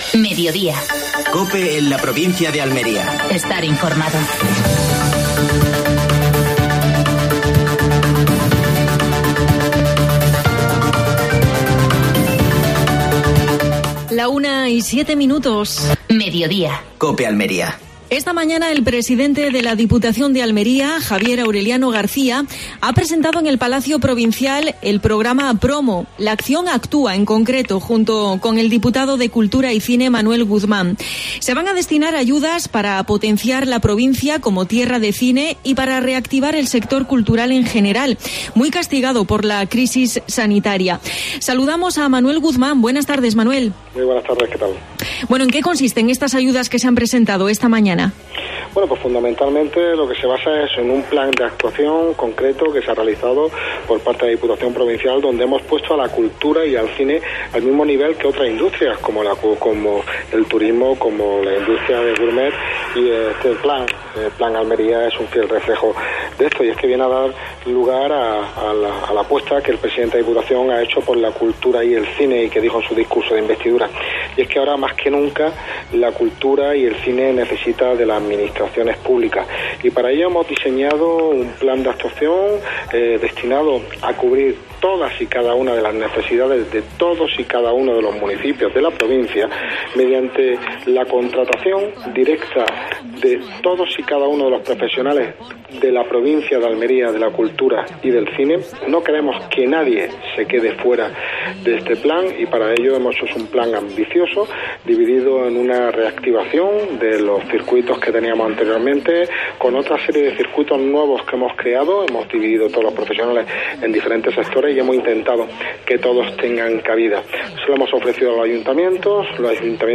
AUDIO: Actualidad en Almería. Entrevista a Manuel Guzmán (diputado de Cultura de la Diputación Provincial de Almería).